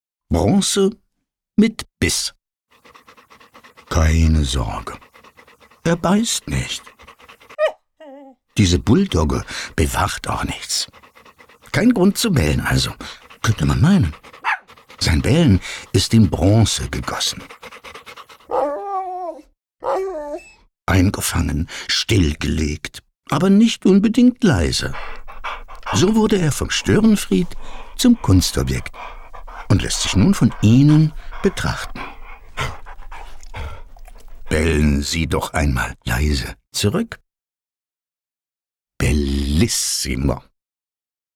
In unserer Reihe KUNST ZUM HÖREN wird die Kunst zum sprechenden Erlebnis: Jedes Werk – auch der BULL-DOG – hat eine Stimme. Für diese Skulptur haben wir ein augenzwinkerndes Audio-Statement verfasst.
Tipp: Einfach lauschen – oder gleich selbst mitbellen!